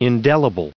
added pronounciation and merriam webster audio
425_indelible.ogg